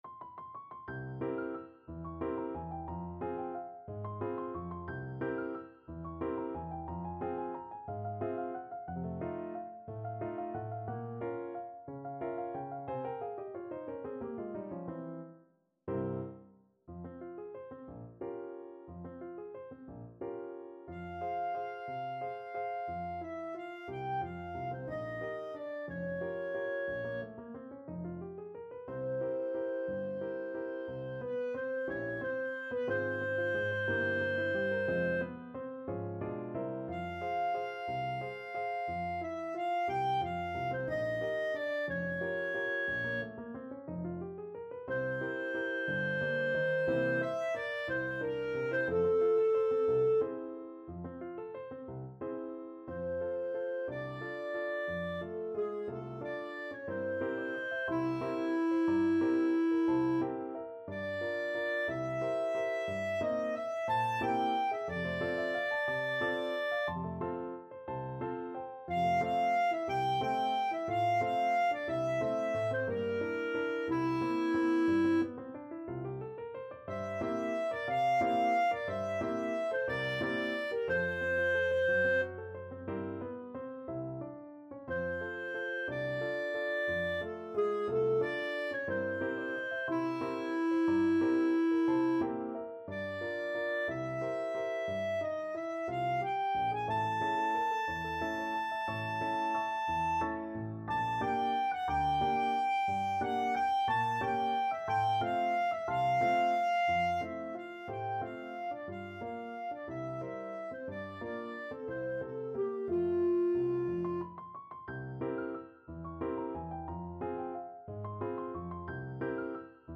Allegro movido =180 (View more music marked Allegro)
3/4 (View more 3/4 Music)
Classical (View more Classical Clarinet Music)